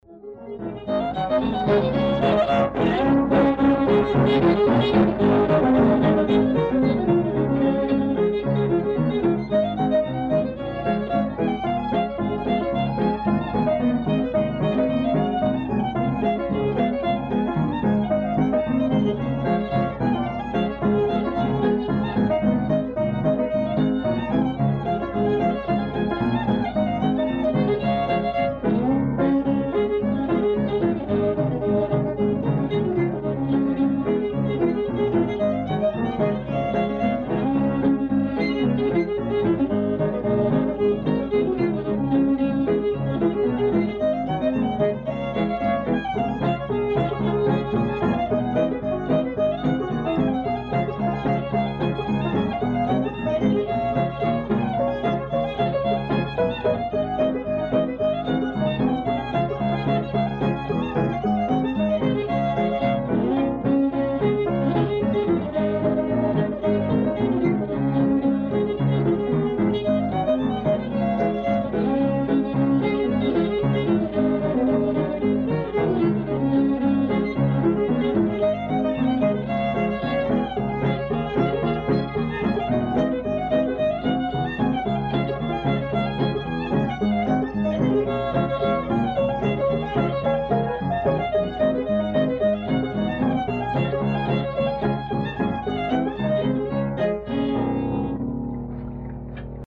dance fiddler
accompanied by his wife on piano. These recordings would have been made in the early 70’s.